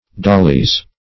Dolly \Dol"ly\, n.; pl. Dollies.